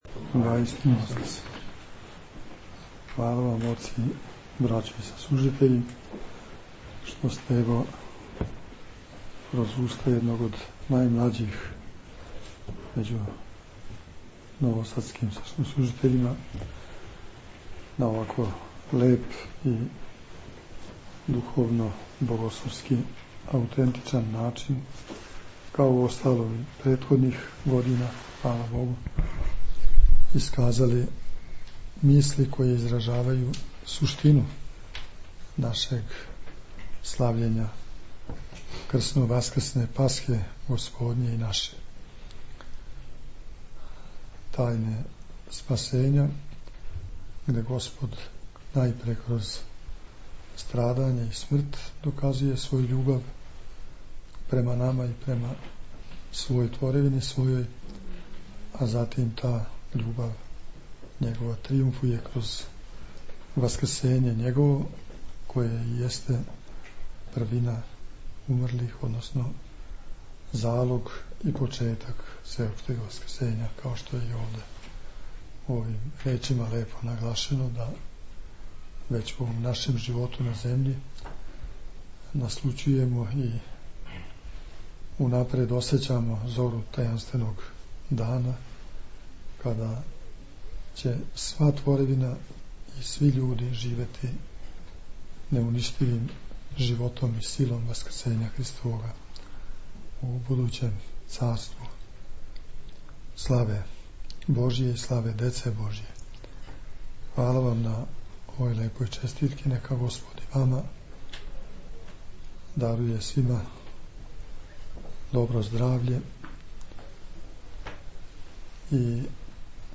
Преосвећени Владика очинском поуком је заблагодарио на честитки својим саслужитељима.